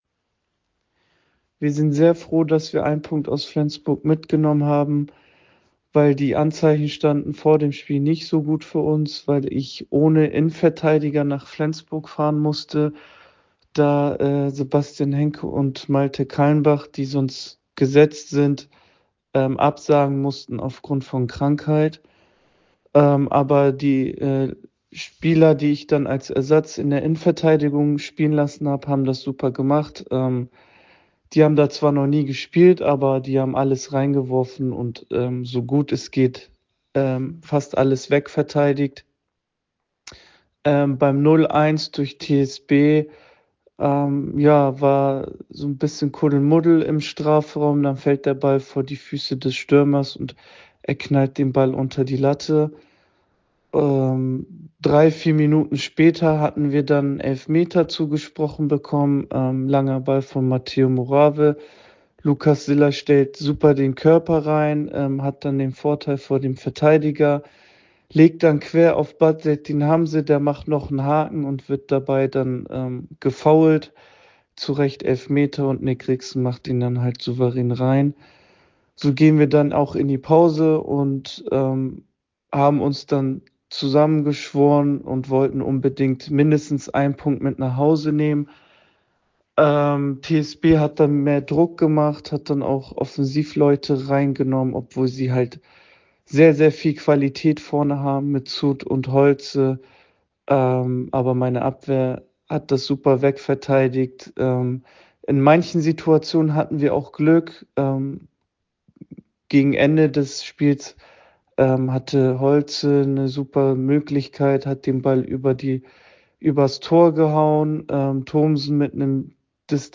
Stimme als Audio